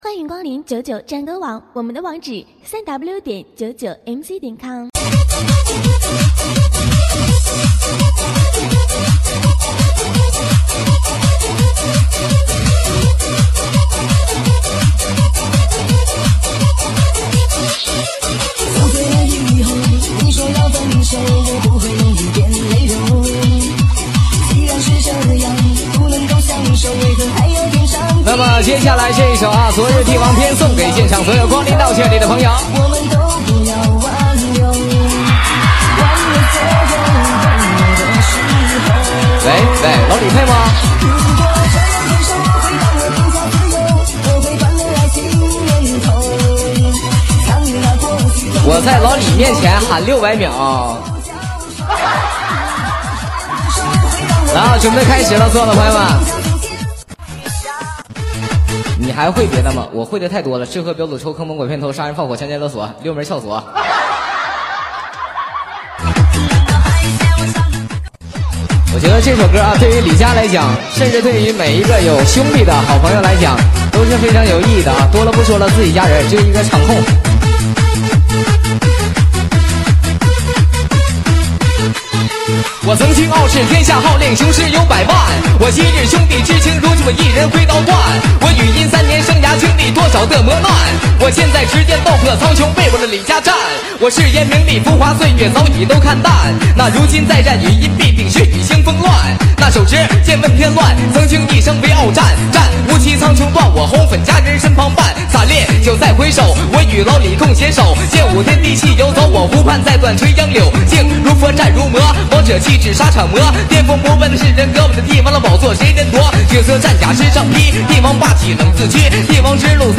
Mc现场活动